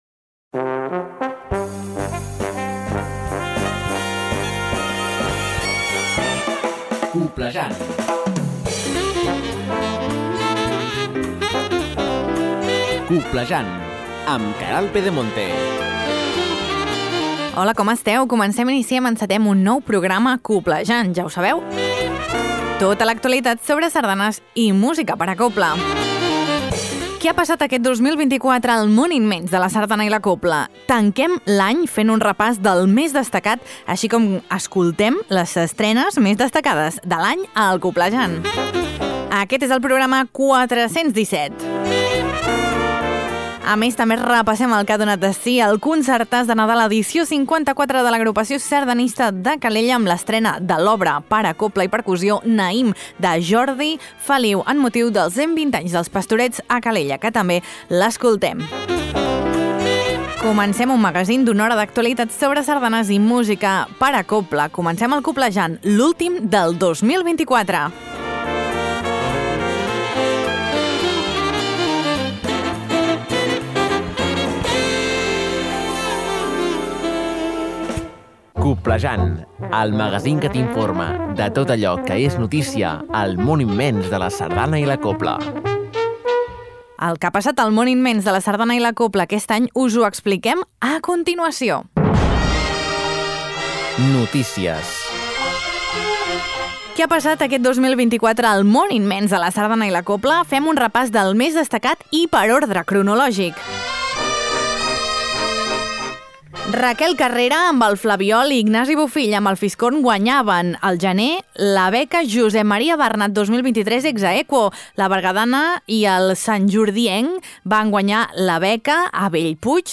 A més, repassem tot el que ha donat de si el Concertàs de Nadal! Edició 54, de l’Agrupació Sardanista de Calella i escoltem l’obra per a cobla i percussió que va estrenar-hi Jordi Feliu “Naïm”, dedicada als 120 anys d’Els Pastorets a Calella!